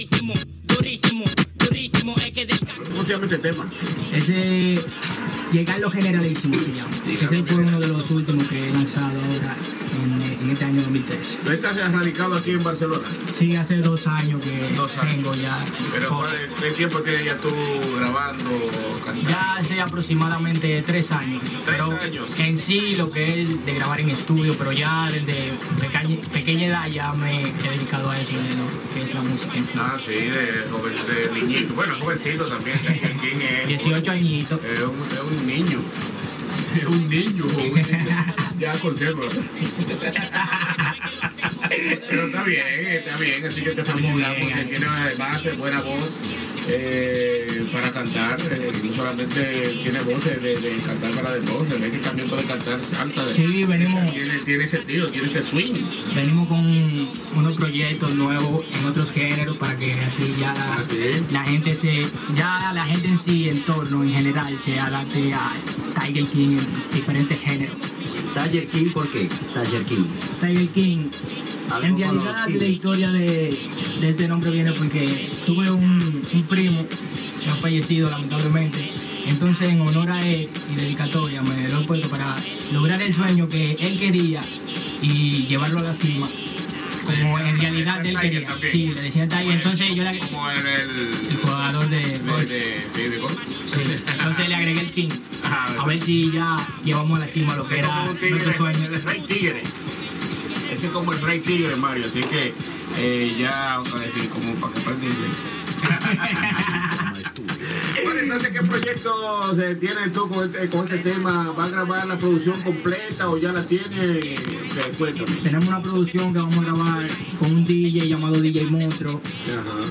etc. Interpretació "a capella" d'un tema, salutacions, adreces de les seves xarxes socials
Musical